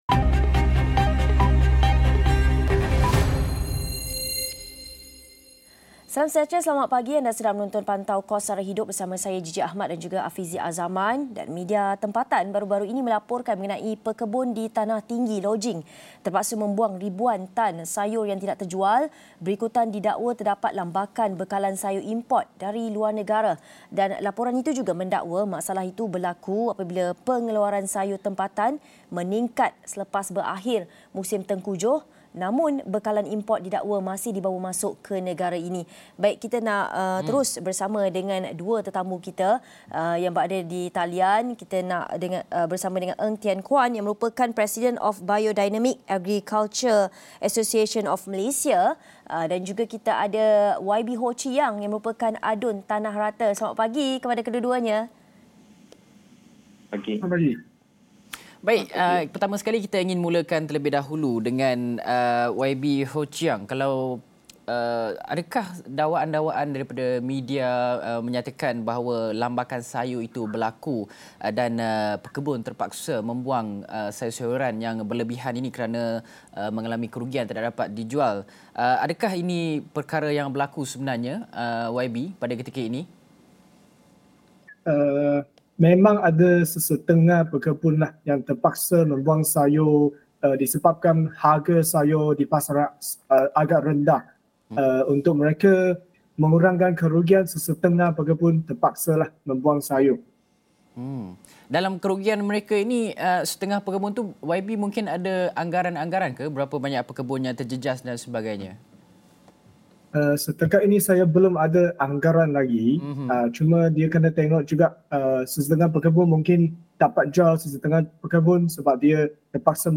Pembekal sayur tempatan dakwa kerugian akibat terpaksa membuang bekalan kerana lambakan sayur dalam pasaran. Masalah berlaku akibat lebihan bekalan sayur import dari luar negara. Dua tetamu akan kongsikan masalah dan harapan mereka untuk menyelesaikan kemelut ini.